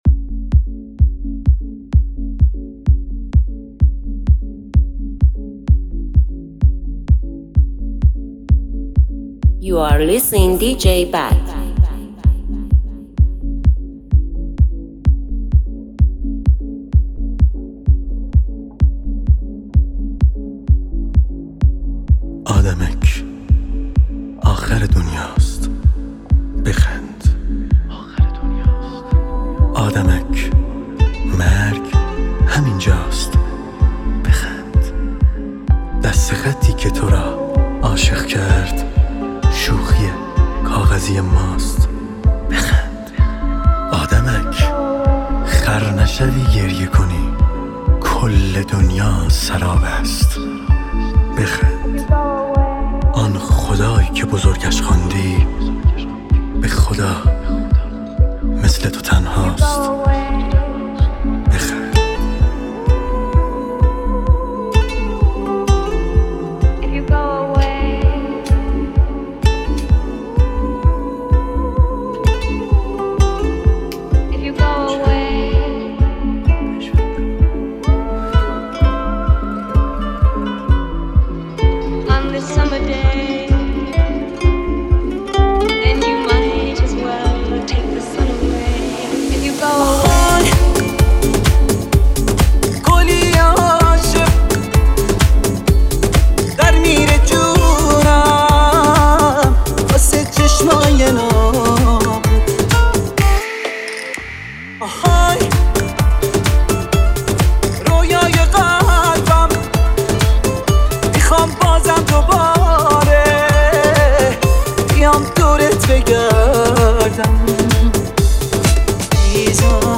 بیس دار سولی دنس